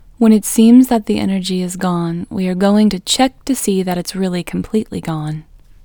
IN – the Second Way – English Female 19